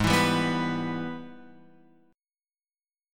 G# Minor Major 7th Sharp 5th